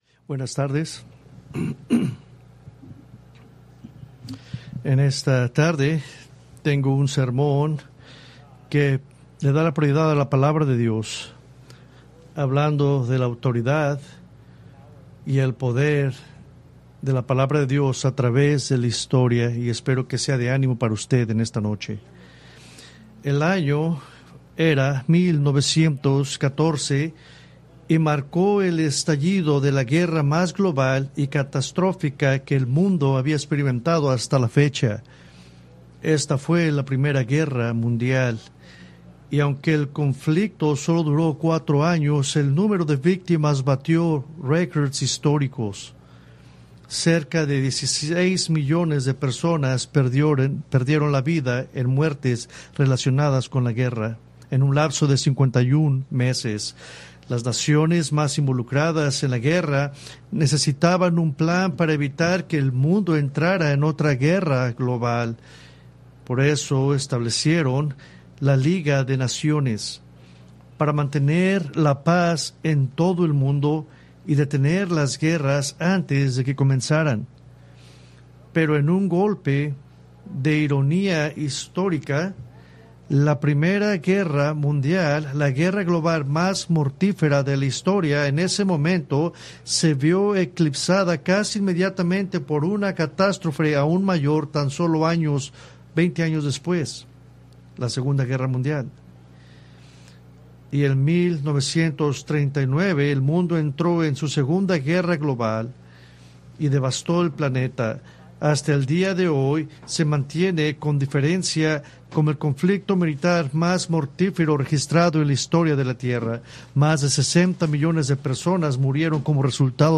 Preached July 20, 2025 from 1 Corintios 14:20-25